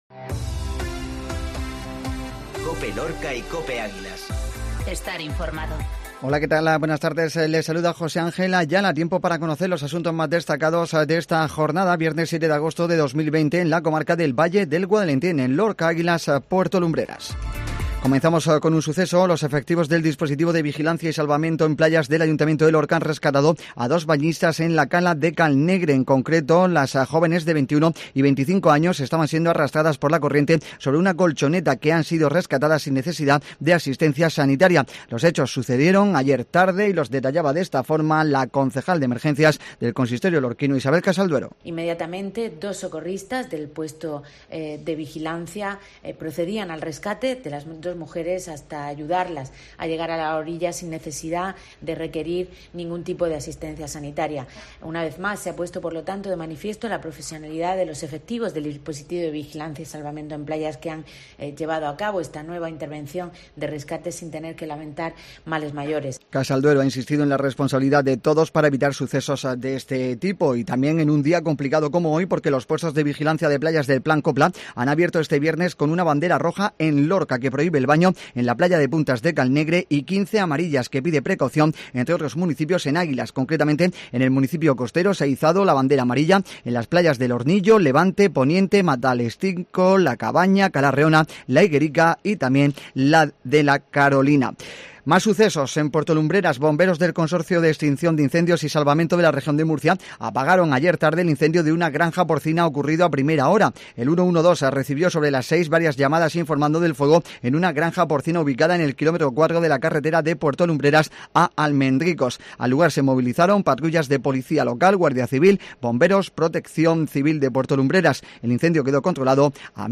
INFORMATIVO MEDIODÍA VIERNES 0708